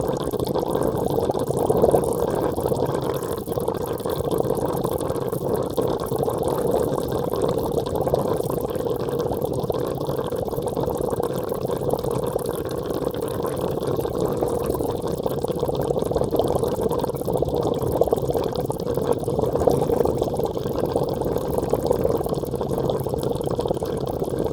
water_bubbling_01_loop.wav